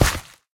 sounds / step / gravel3.ogg
gravel3.ogg